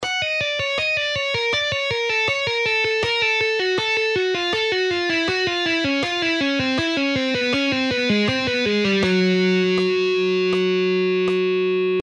Cascading style guitar licks
Lesson 3: Legato Exercise In Cascade -Dimebag Darrell Style
In half speed:
7.-Legato-Exercise-In-Cascade-Dimebag-Darrell-Style-Half-Speed-1.mp3